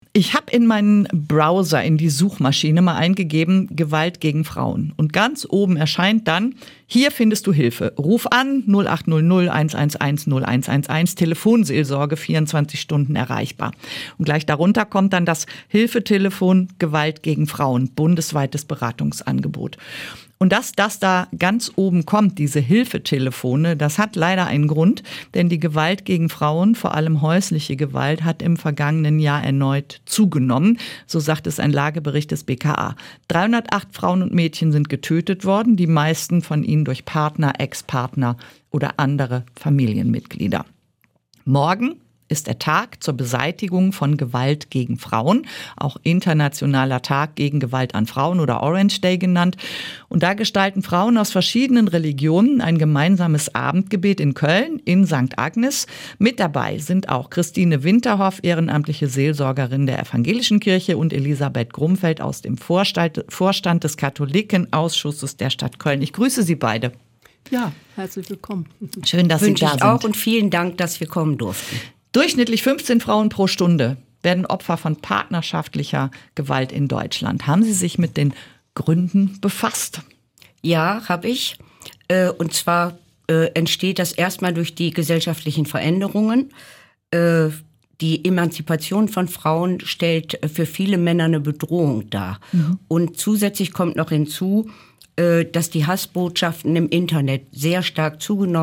Interview-zum-Tag-gegen-Gewalt-an-Frauen.mp3